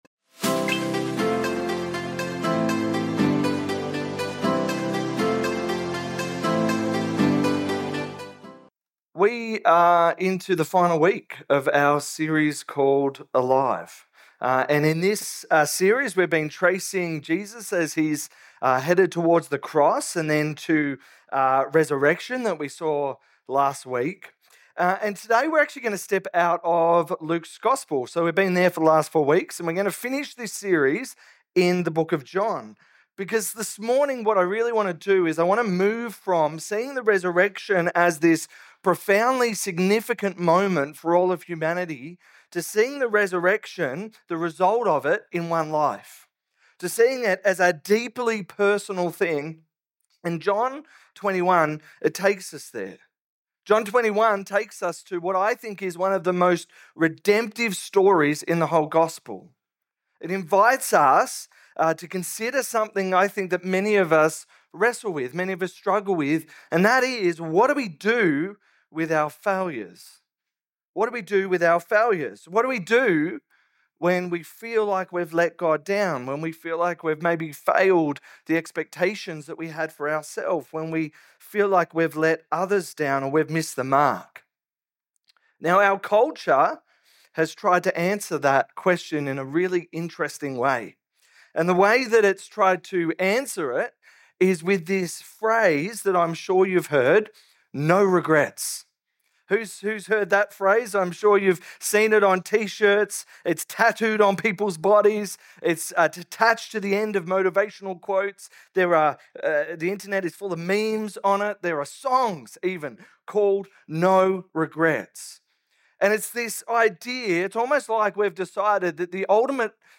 In John 21, we see that failure isn't the end of the story. Jesus restores Peter and calls him forward - and He does the same for us. Watch the final message from our Alive series!